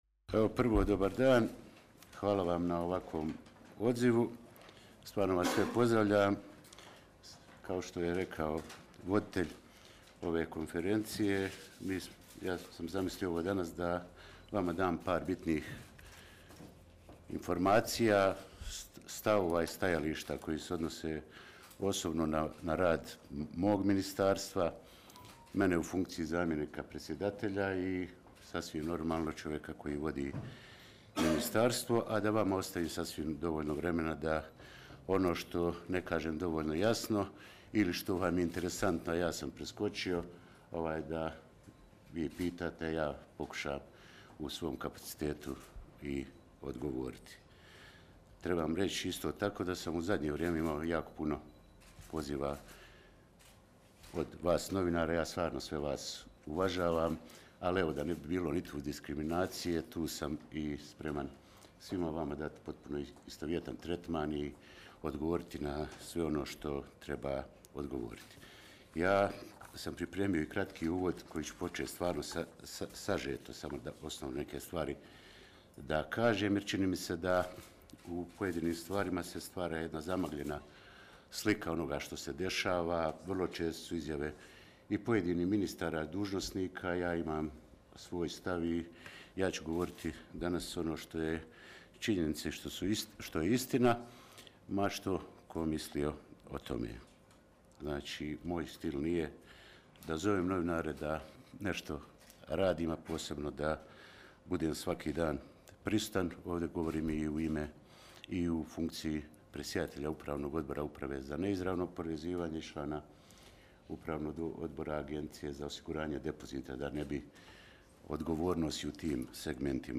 Konferencija za medije ministra Bevande (AUDO)
Zamjenik predsjedatelja Vijeća ministara BiH i ministar financija i trezora Vjekoslav Bevanda održao je u Sarajevu konferenciju za medije.